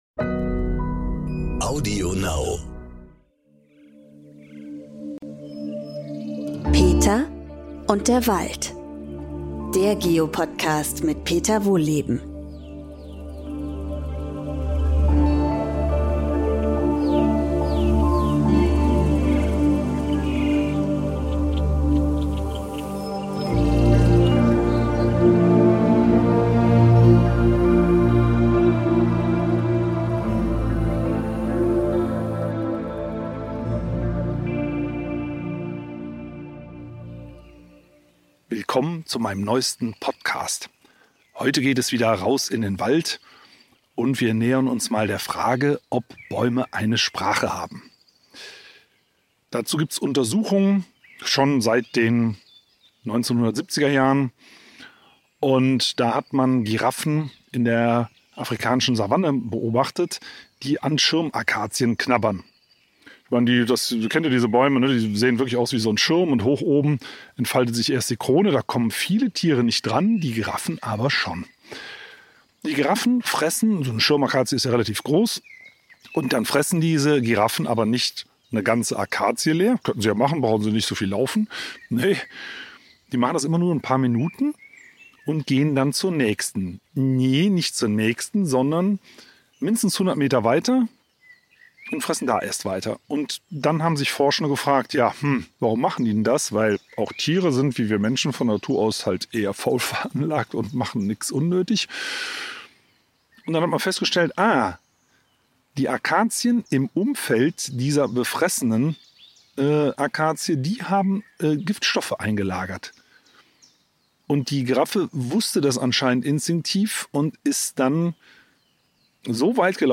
Wieder geht’s in den Wald! Peter Wohlleben spaziert mit uns durch den alten Buchenwald und erläutert, wie Bäume eigentlich miteinander kommunizieren, was es mit dem WoodWideWeb auf sich hat und warum Sprache nicht nur Schallweiterleitung ist.